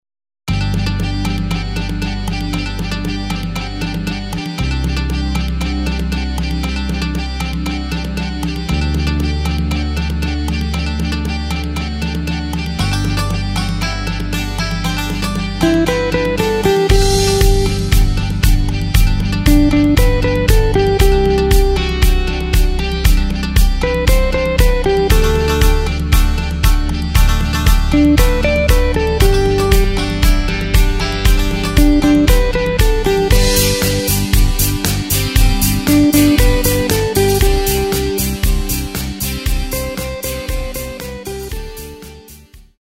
Takt:          4/4
Tempo:         117.00
Tonart:            G